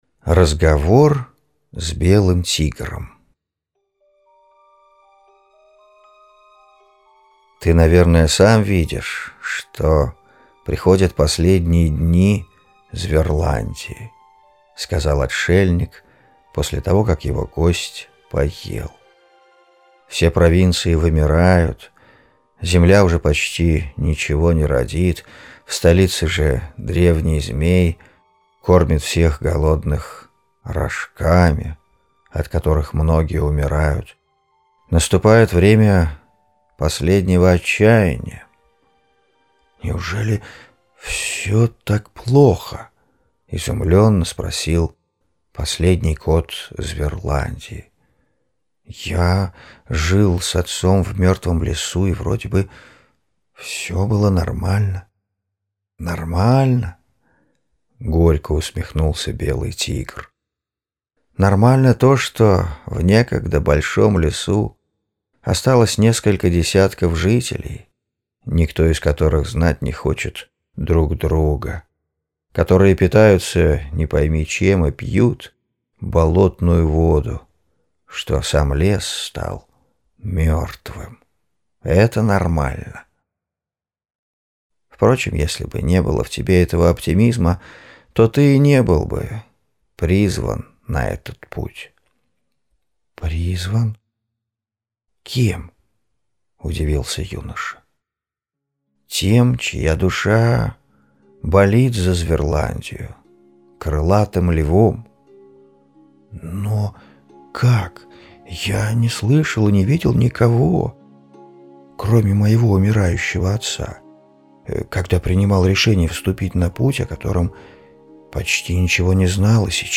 Posted in Аудио , Хроники Зверландии аудиоверсия Последний Кот Зверландии. 3.